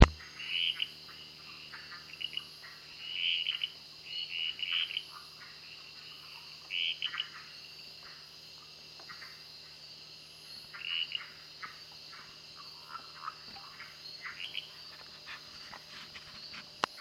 During rainy season call from low emergent vegetation at the edge of a pond, from dusk throughout most of the night.
Call is a high-pitched squark or creeek, repeated at frequent intervals - easily confused with H.microcephala to the untrained ear.